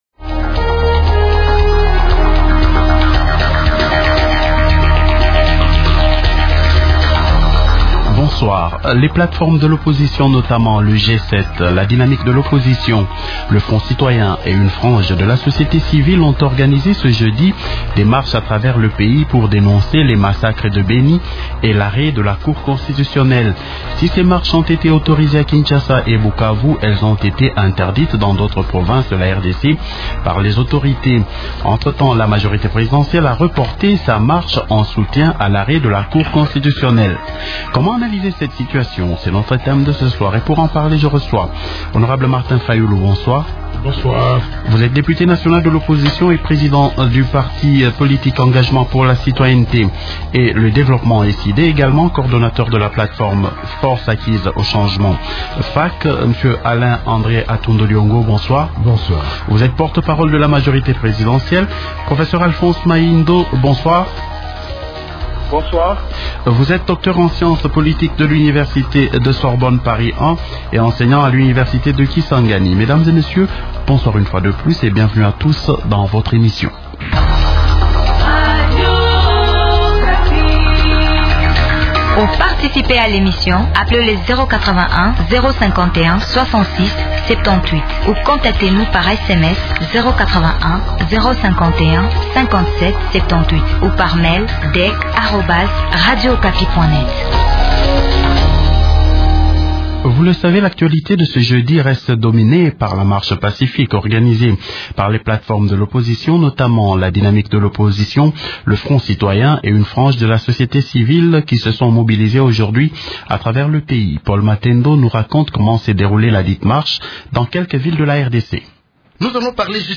Participent au débat de ce soir :